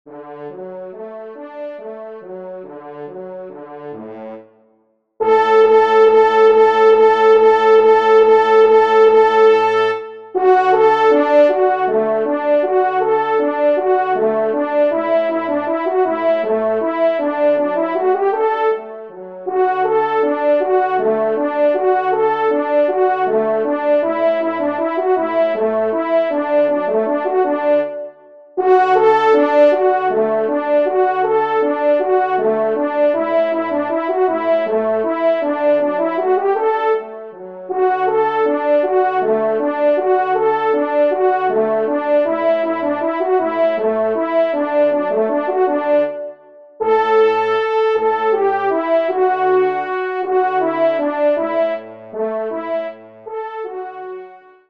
Valse
Genre :  Divertissement pour Trompes ou Cors en Ré (Valse)
1e Trompe